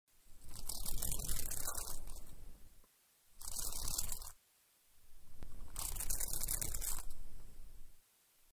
Здесь вы найдёте разные варианты записей: от нежного шелеста до интенсивного жужжания во время быстрого полёта.
Стрекоза порхает